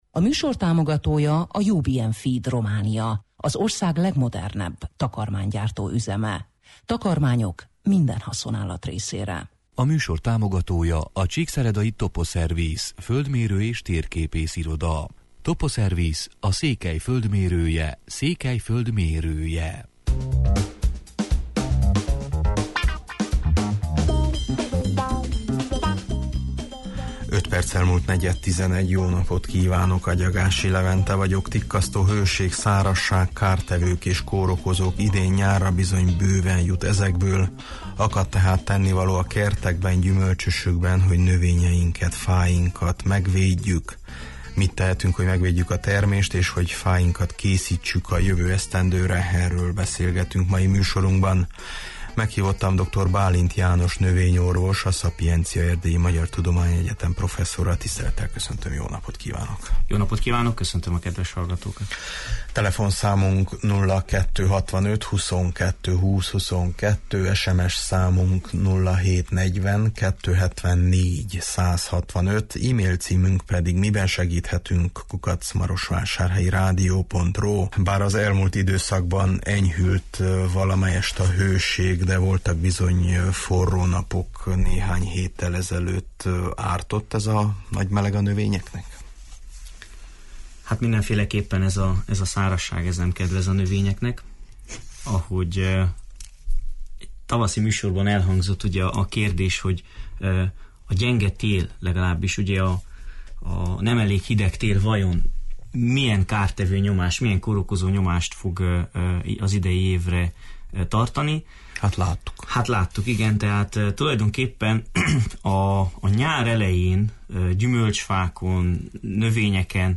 Tikkasztó hőség, szárazság, kártevők és kórokozók – idén nyárra bizony bőven jut ezekből, akad tehát tennivaló a kertekben, gyümölcsösökben, hogy növényeiket, fáinkat megvédjük. Mit tehetünk, hogy megvédjük a termést és hogy fáinkat készítsük a jövő esztendőre – erről beszélgetünk mai műsorunkban.